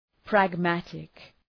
Προφορά
{præg’mætık}